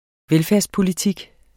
Udtale [ ˈvεlfεɐ̯s- ]